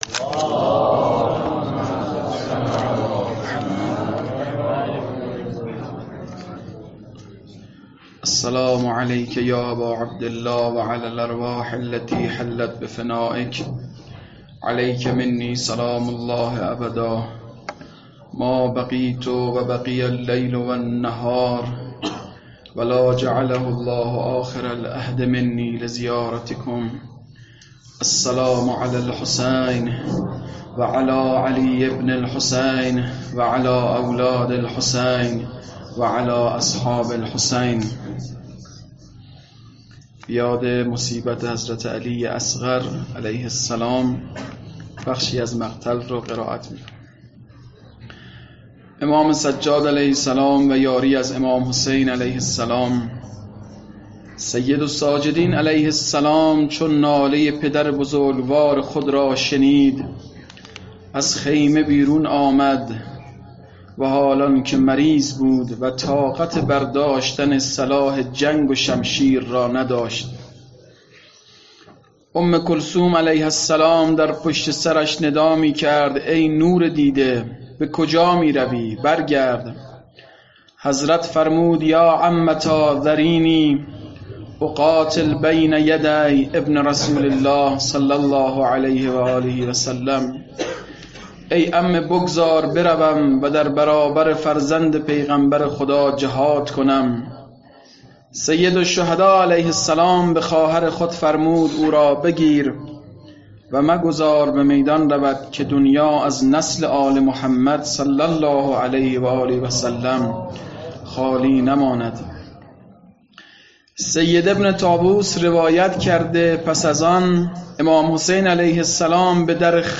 مقتل خوانی روز7محرم2.mp3
• مقتل حضرت علی اصغر, مقتل مهیج الاحزان, صوت مقتل خوانی, مقتل خوانی, روضه علی اصغر